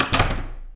snd_doorclose_ch1.wav